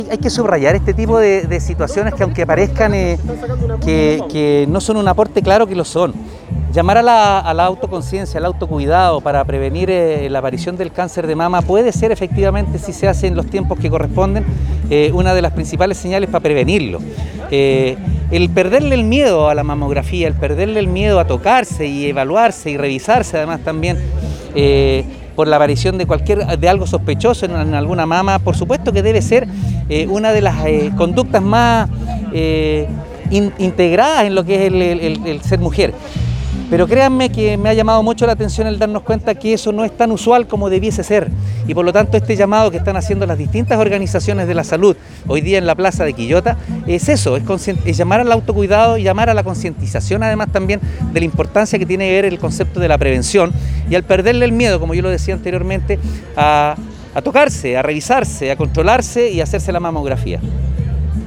Una feria preventiva e informativa para la Concientización del Cáncer de Mama, realizó la Municipalidad de Quillota a través del Departamento de Salud Municipal en la plaza central de la ciudad.
La autoridad comunal efectuó un llamado a las mujeres de la comuna, para acercarse a los centros de salud y poder realizarse los exámenes preventivos correspondientes.
alcalde-oscar-calderon.mp3